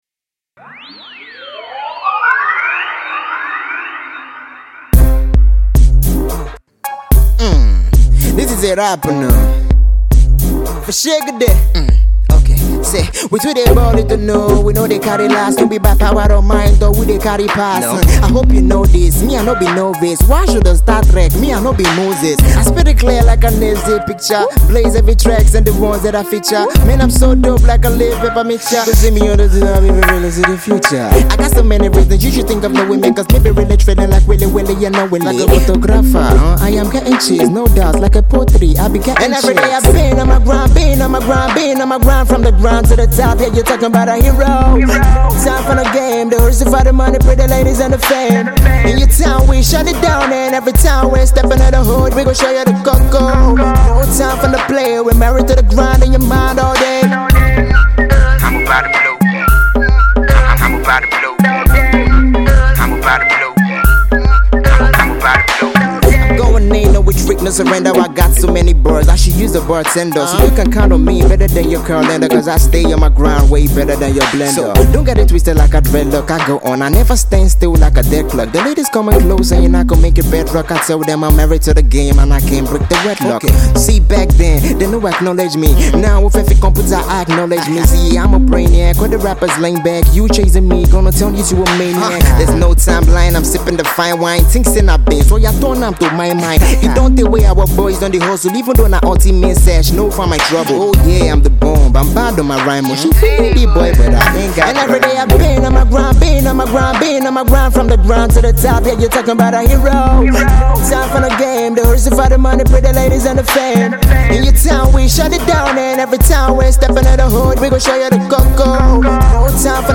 is full of witty lyrics and punch lines
up-and-coming rapper